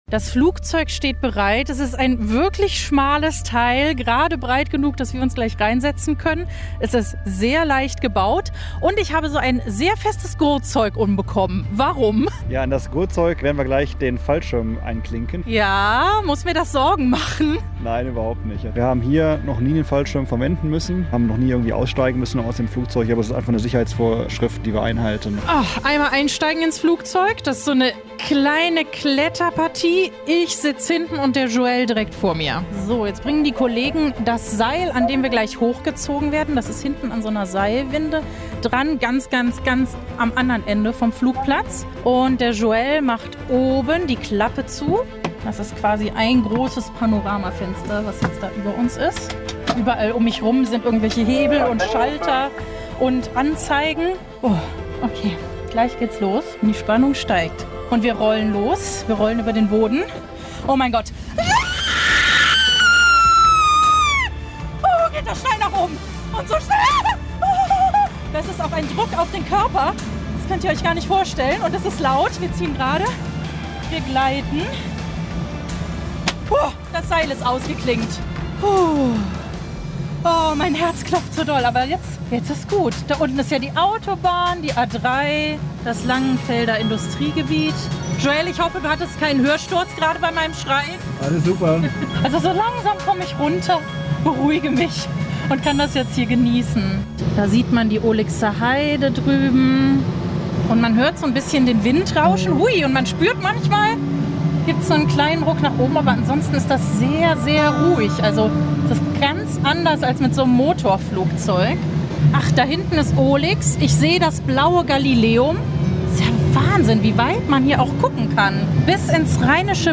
Anzeige crop_free crop_free crop_free crop_free crop_free crop_free chevron_left chevron_right Anzeige Reportage